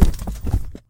Body_fall2.ogg